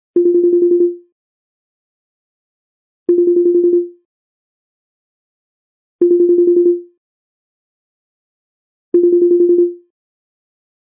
ringing.34d59093.mp3